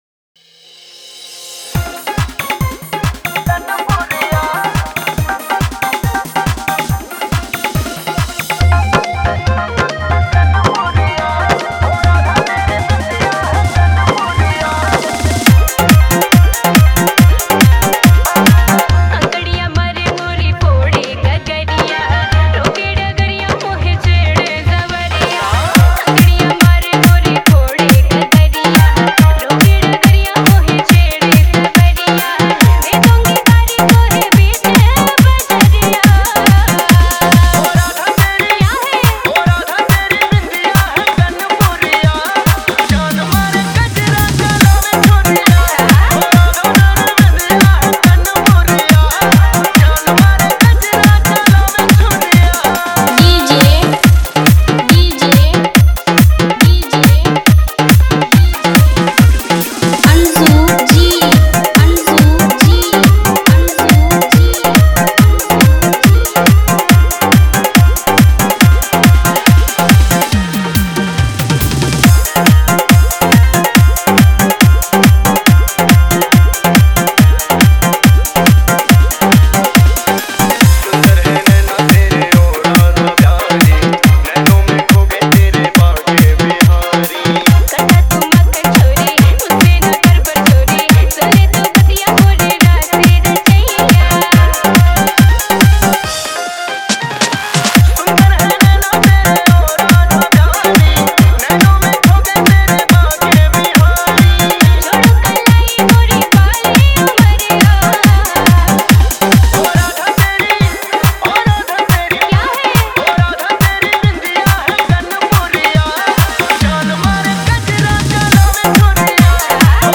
Janmastami special DJ song, Radha Krishna DJ remix
Bhajan remix DJ song
Radha Krishna dance DJ song, Radha Krishna devotional remix
Janmashtami bhajan remix song